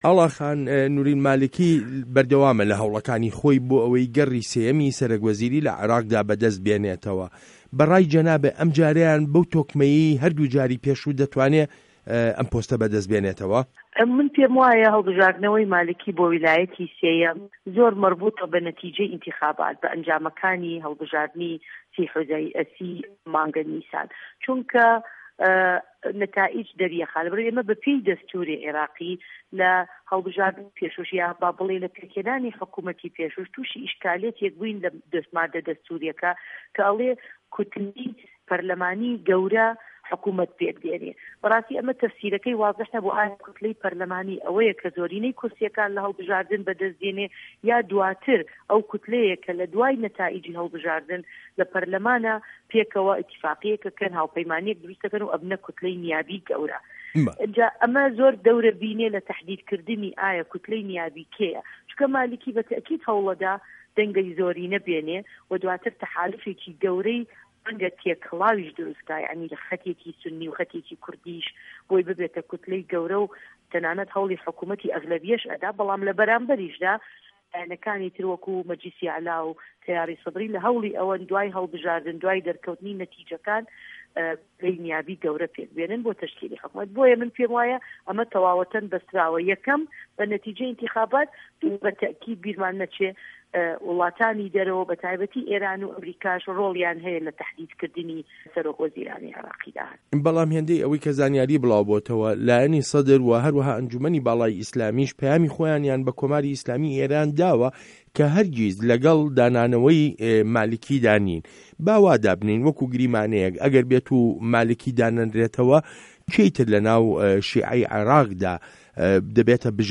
وتووێژ له‌گه‌ڵ ئاڵا تاڵه‌بانی